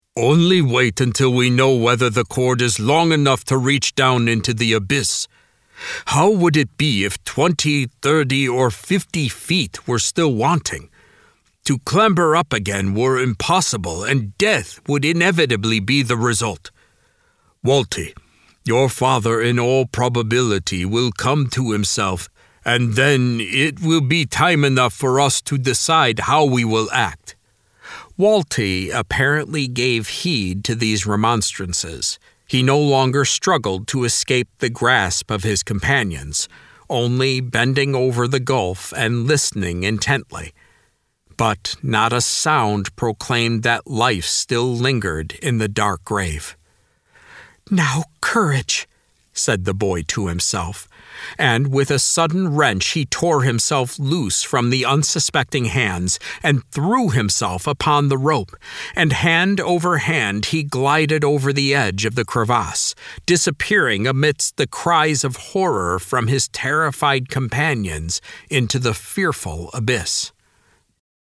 This is an audiobook, not a Lamplighter Theatre drama.